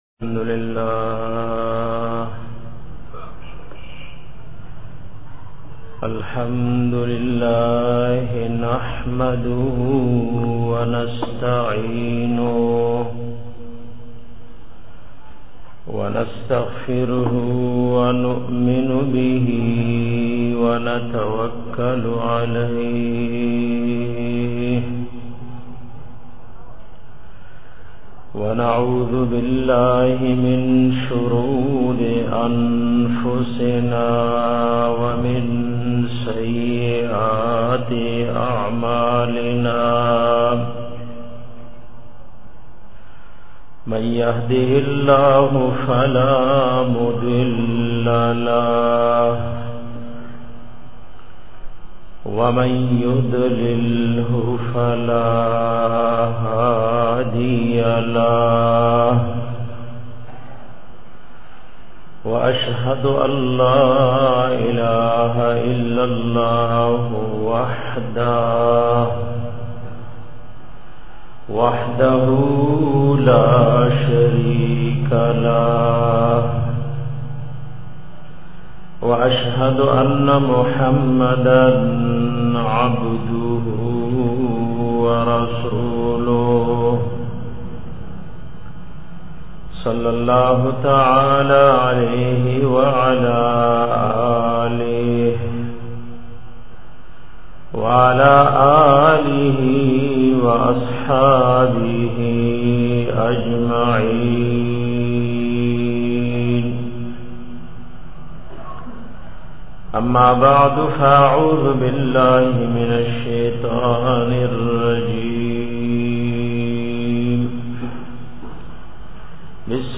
bayan da akhtar pa wraz pa jamia islamia k